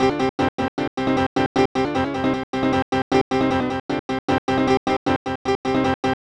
And a few crazy loops, mostly done with random kits and functions.
Blip_HypnoPiano.wav